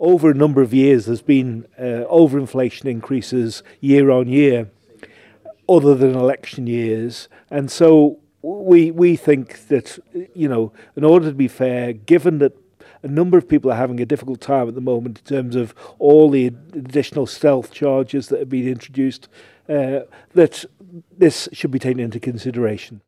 MLP leader and MLC David Cretney says the party had to take a stand: